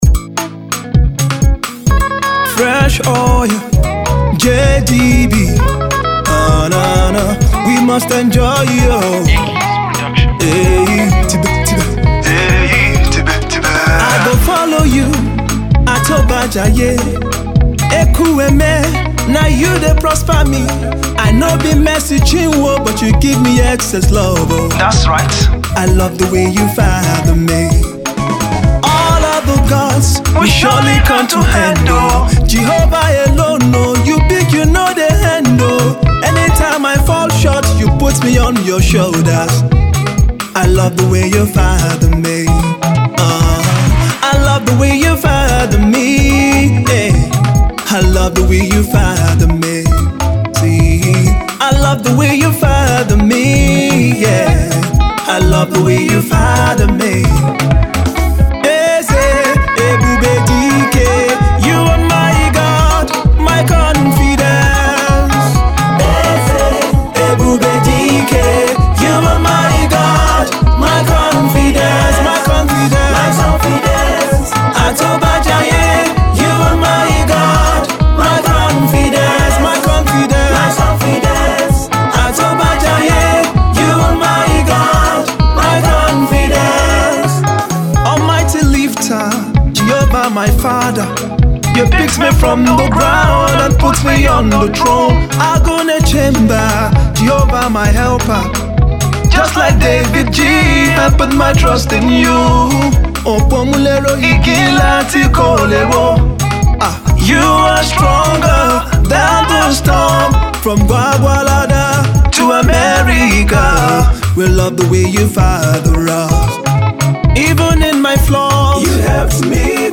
a danceable praise song